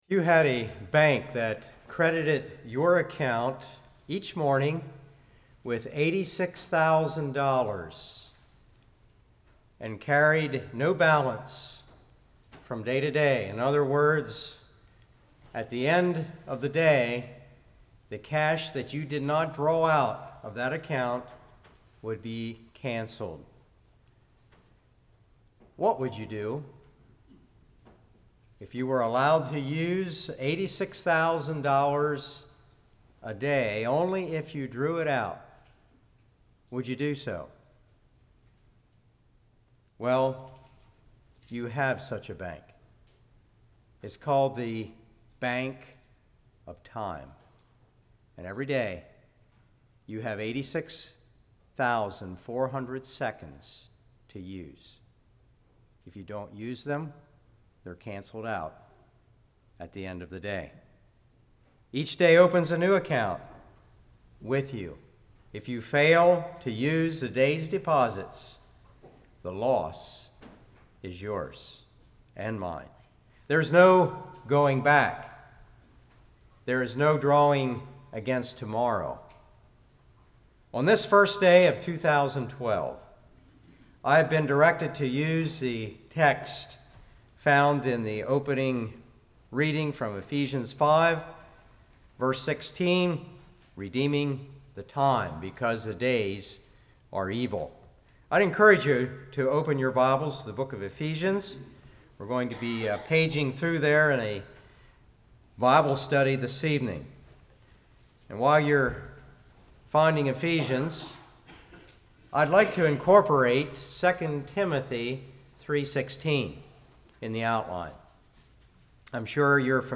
Ephesians 5:1-17 Service Type: Evening Rescue time Satan robs our time What would Jesus do?